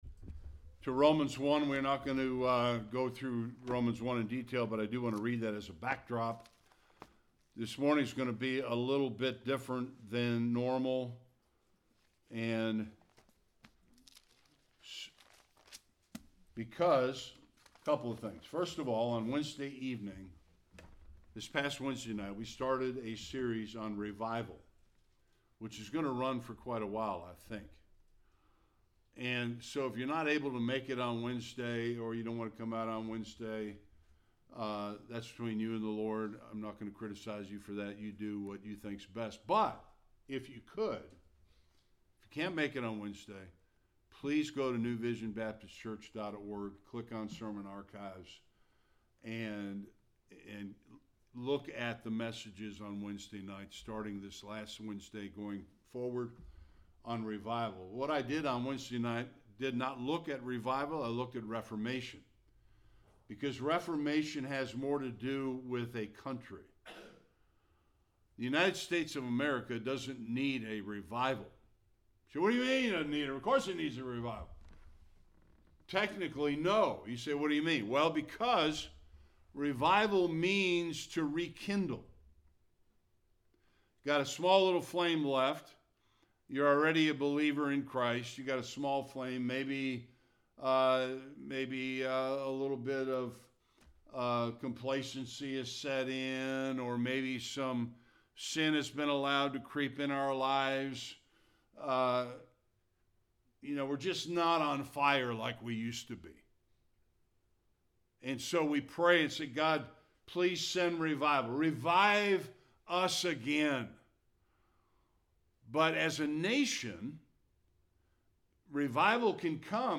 1-7 Service Type: Sunday Worship America needs a reformation